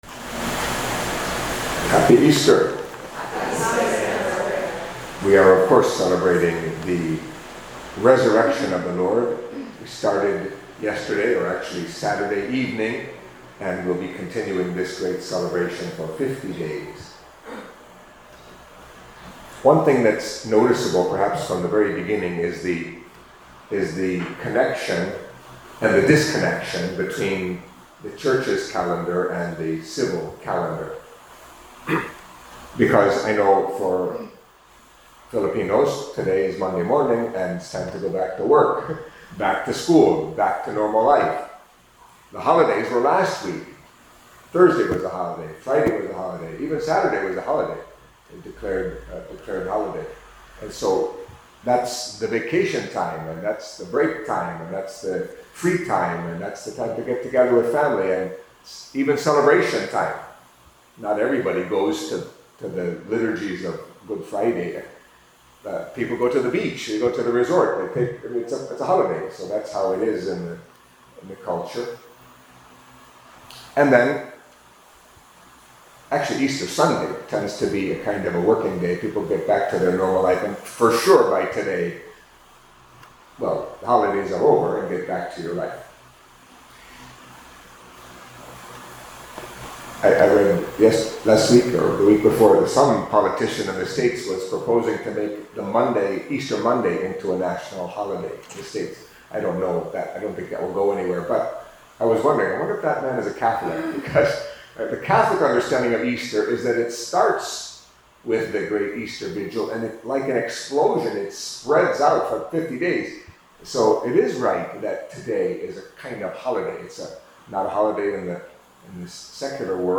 Catholic Mass homily for Monday in the Octave of Easter